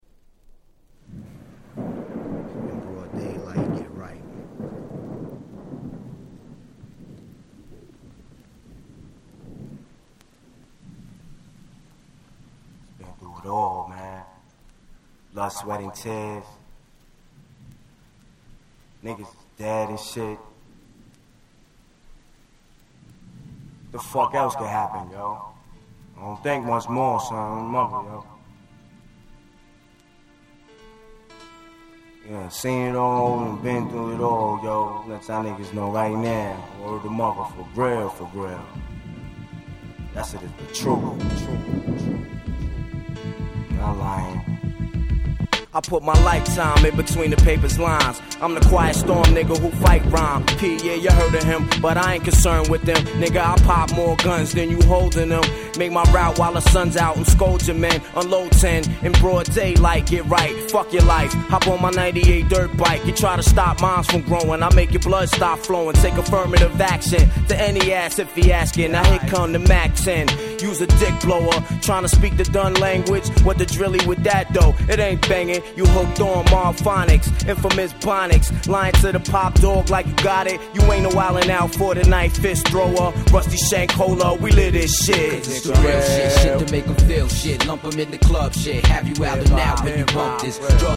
99' Smash Hit Hip Hop !!
90's Boom Bap ブーンバップ